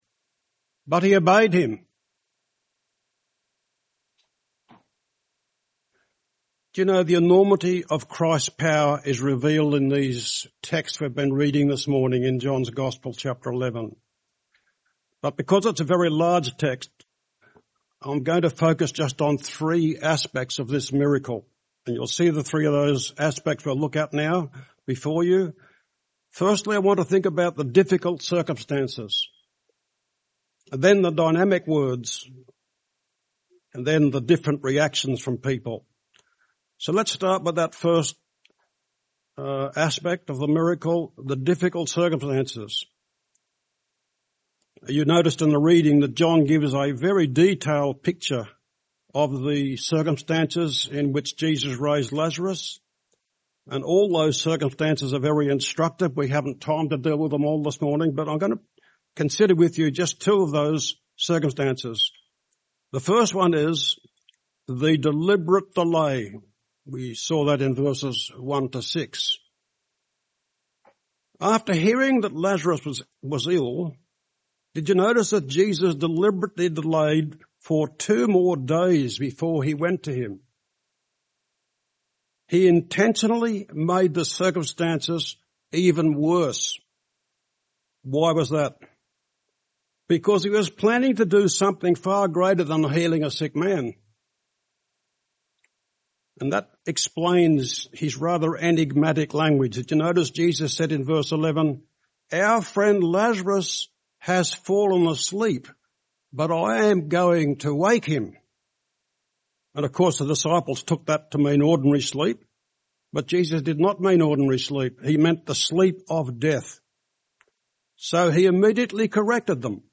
A sermon on Jesus’ raising of Lazarus, showing Jesus’ power to give eternal life.
N.b. the first few moments of this sermon were not recorded